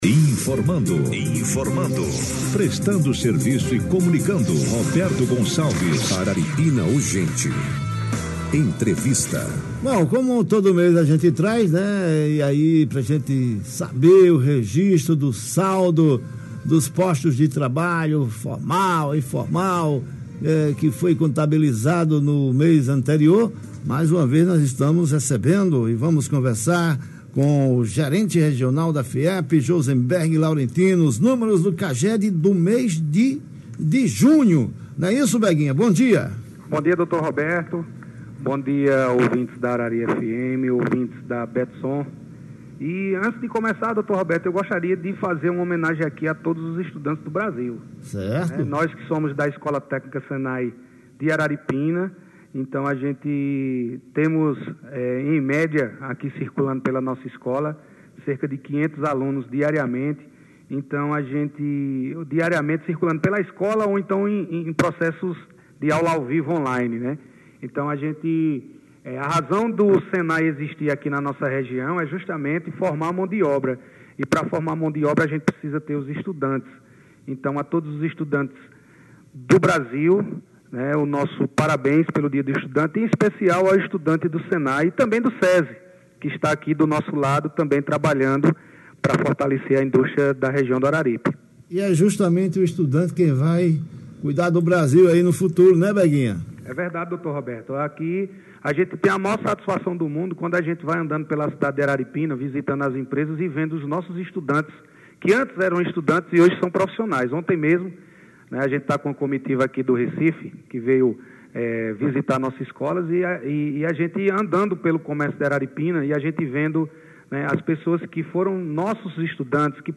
em entrevista exclusiva para o Blog do Roberto.